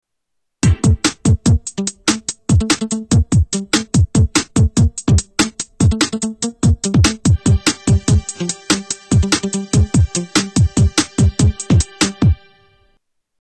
Stealth Seamless Loop B
Tags: Sound effects Espionage music Espionage Stealth Music